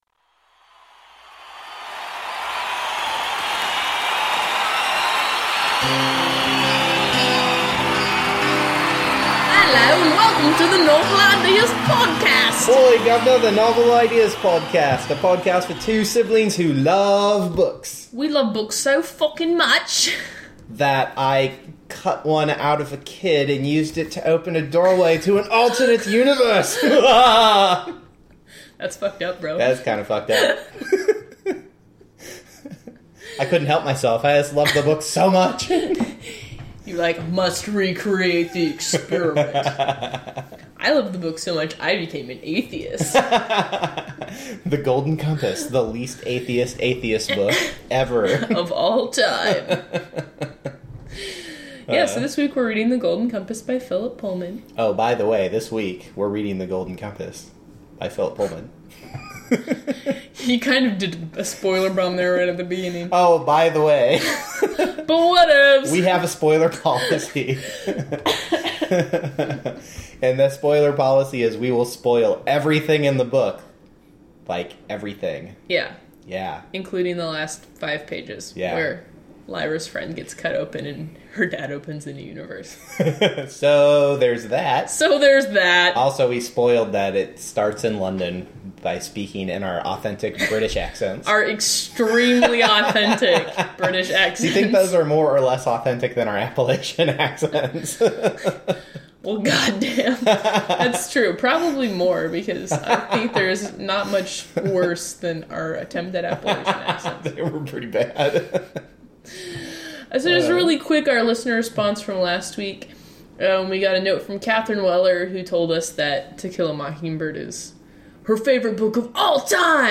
Special appearance this week by Callie the cat, who had many opinions about this book and whether it should prevent podcast hosts from paying attention to her.